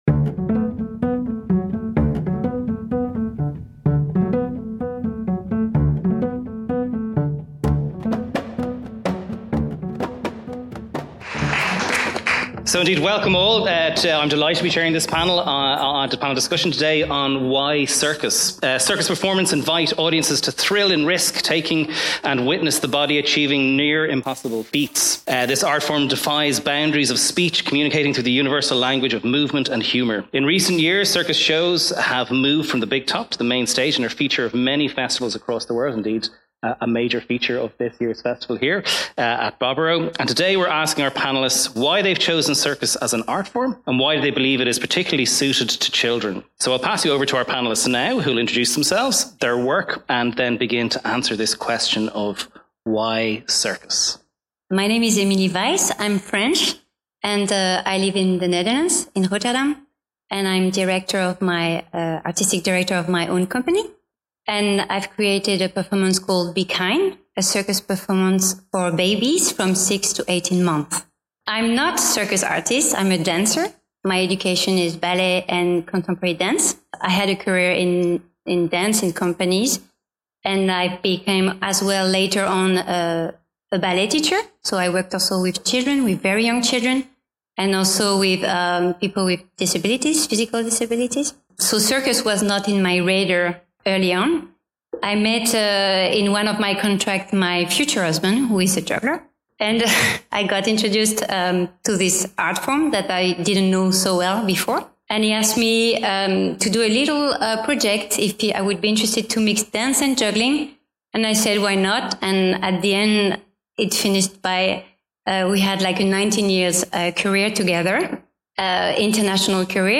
This discussion centres on what makes circus such a compelling choice for both presenters and audiences. A discussion